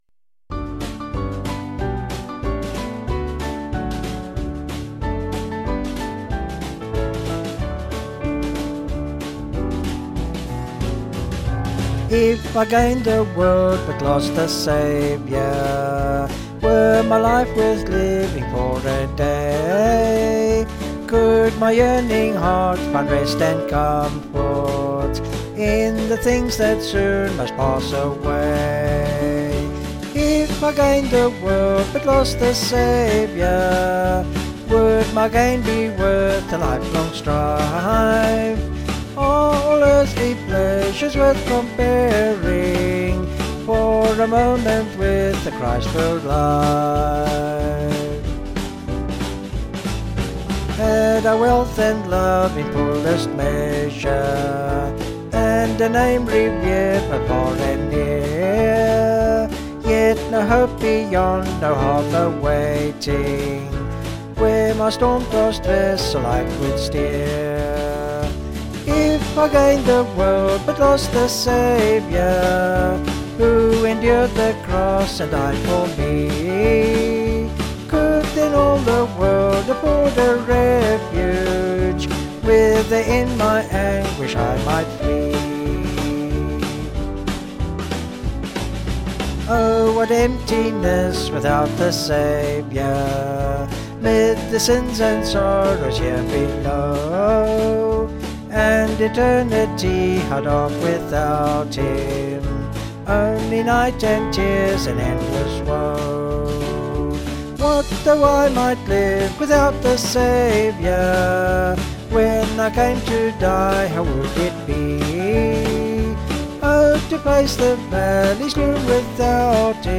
Small Band
(BH)   4/D-Eb
Vocals and Band   263.9kb